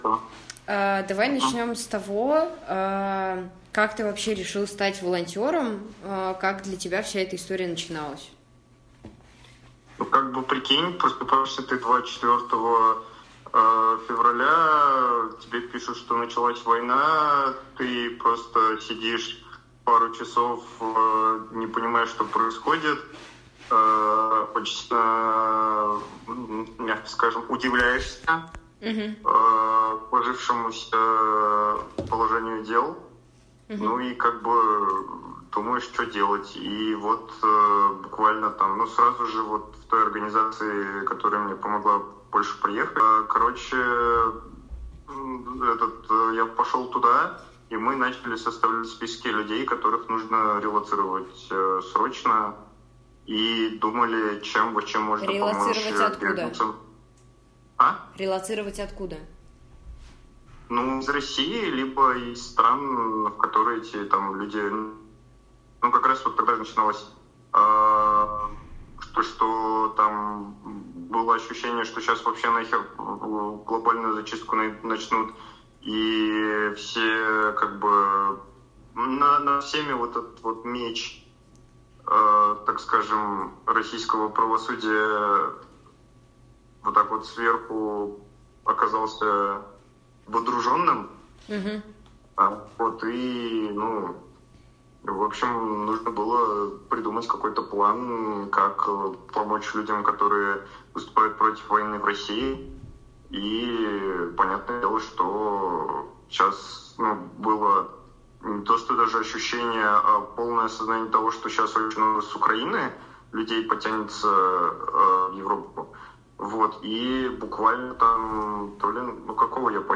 Когда эвакуировались из Харькова, по рельсам метро пешком шли — личные свидетельства войны в Украине, архив «Службы поддержки»